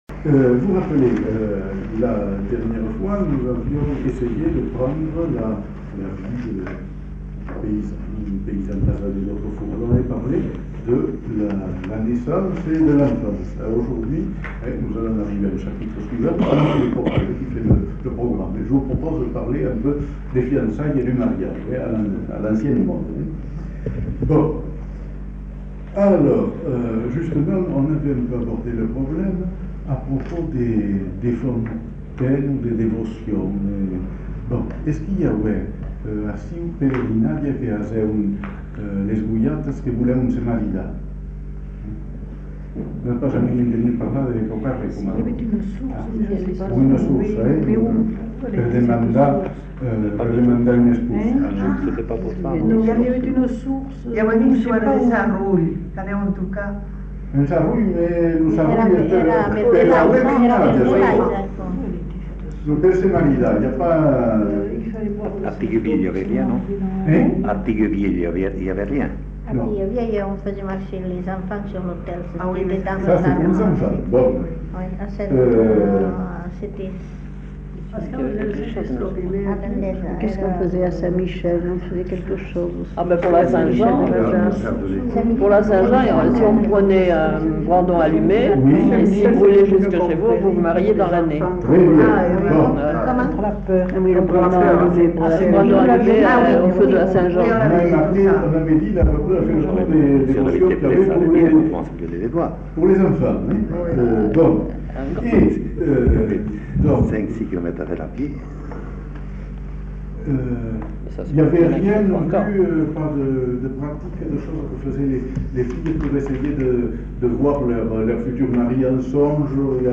Lieu : Bazas
Genre : témoignage thématique